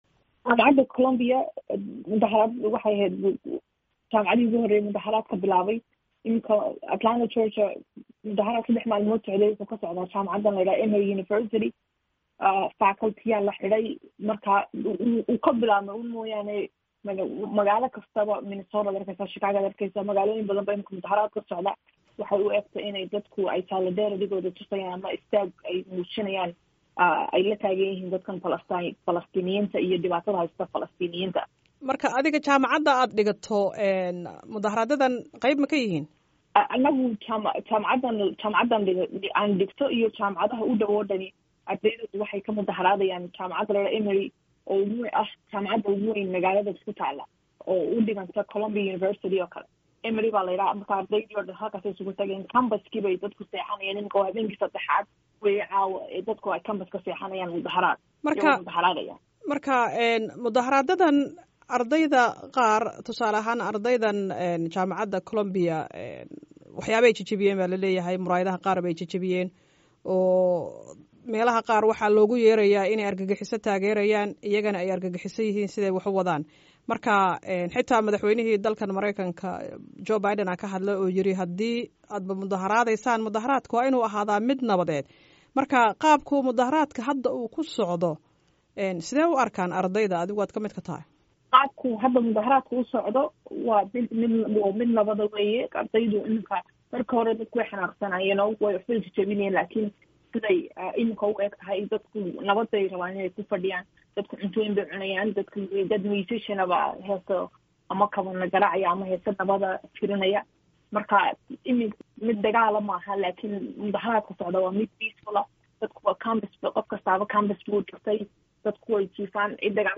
Wareysi dibadbaxyada ardayda